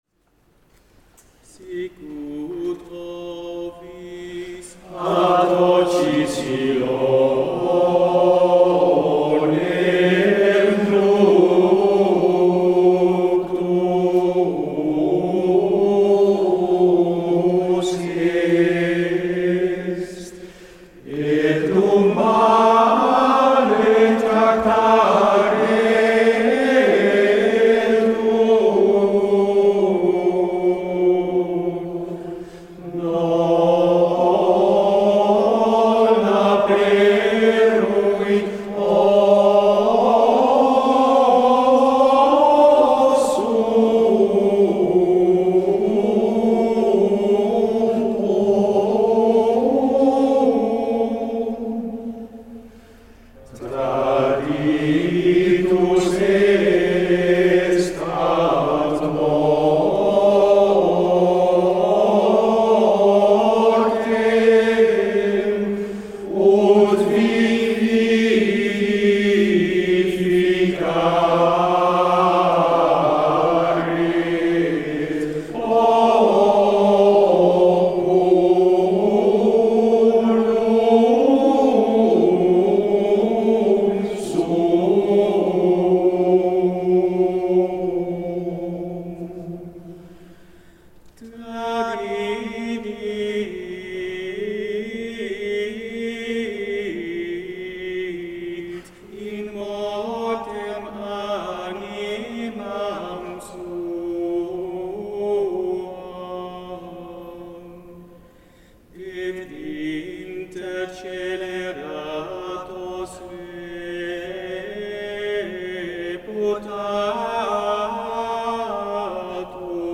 Dag van het gregoriaans, Utrecht 9 maart 2019.